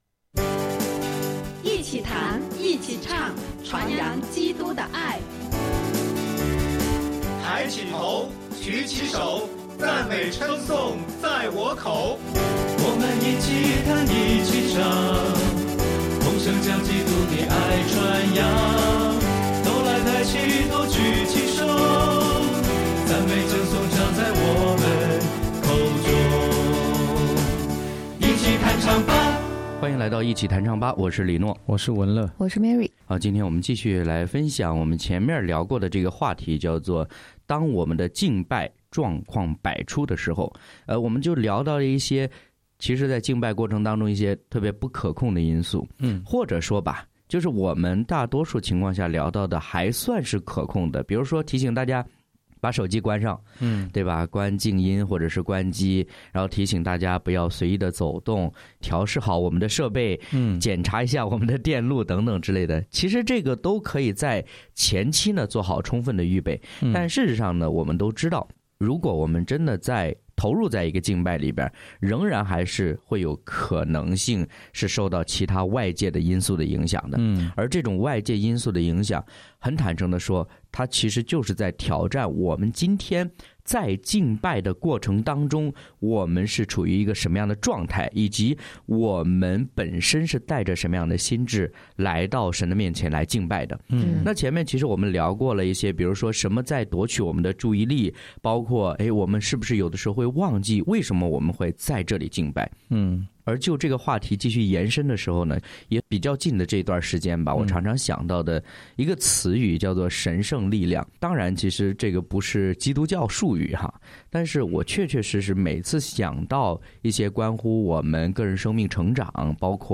诗歌弹唱，享受赞美